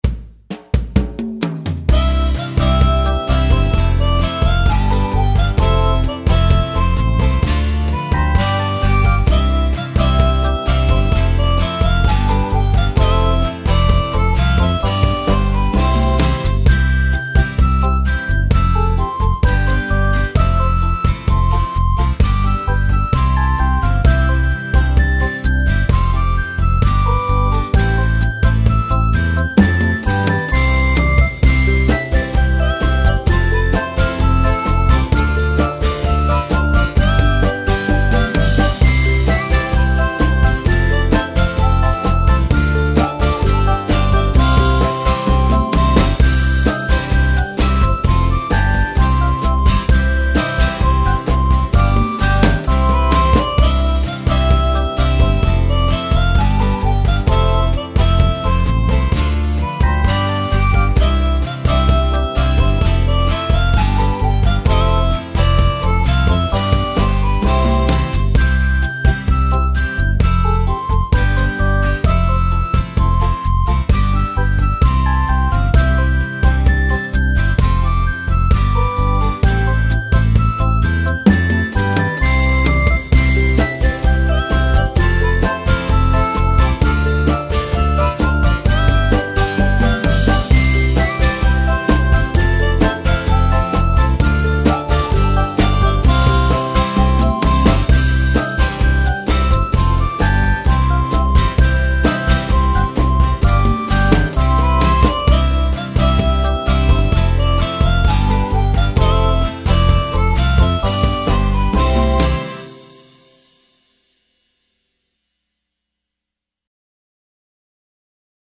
看海(純伴奏版) | 新北市客家文化典藏資料庫